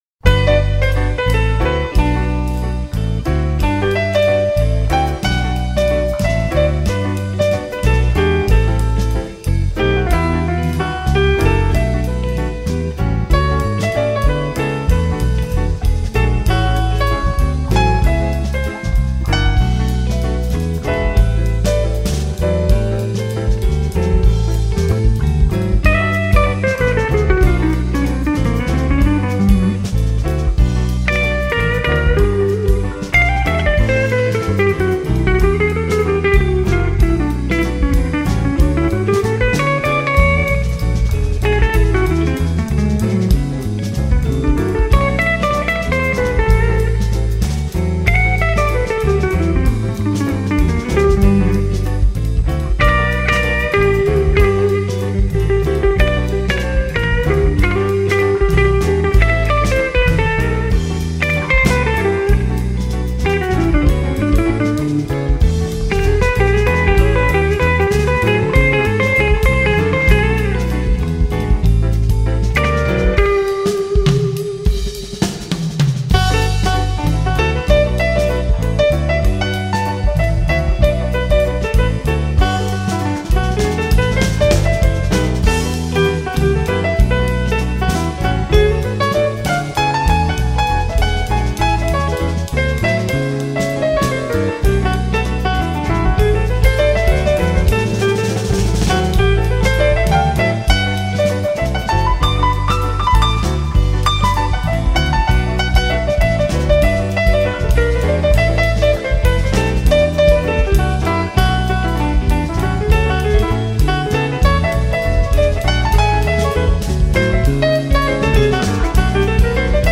558   03:01:00   Faixa: 1    Jazz
Gravado no Teatro 4 de Setembro